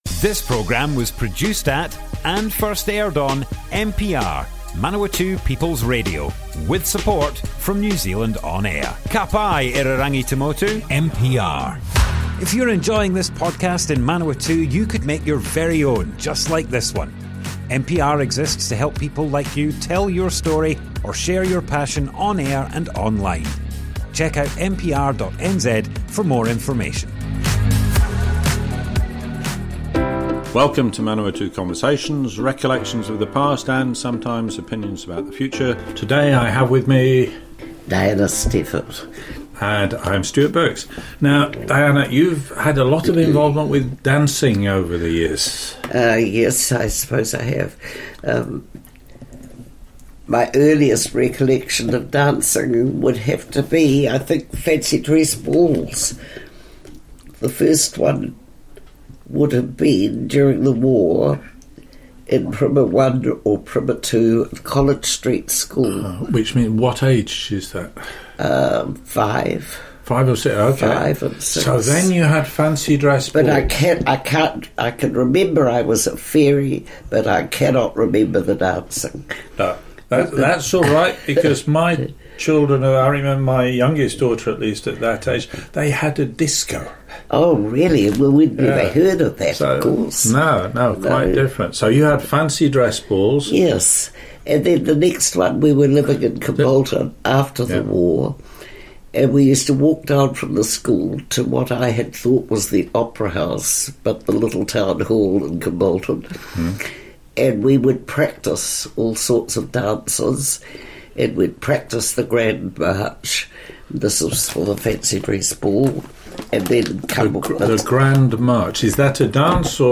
Manawatu Conversations Object type Audio More Info → Description Broadcast on Manawatu People's Radio, 31st January 2023.
oral history